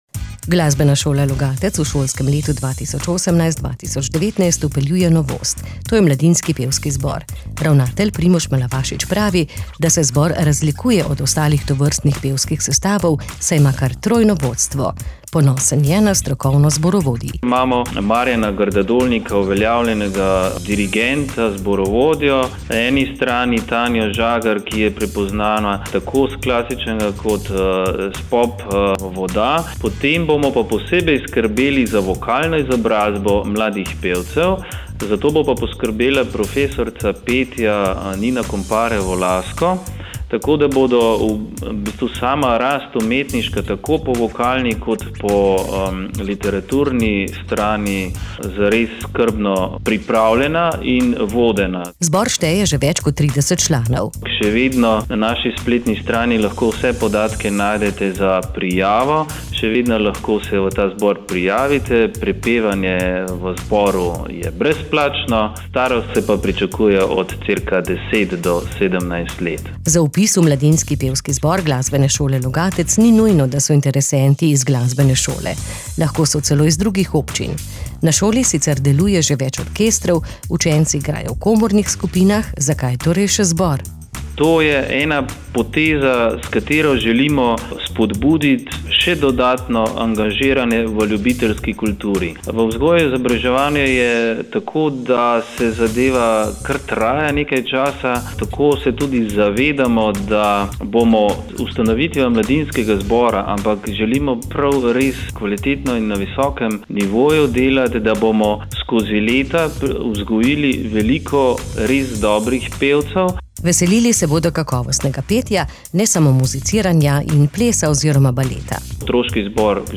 kr18-pevski-zbor-glasbene-sole-logatec.mp3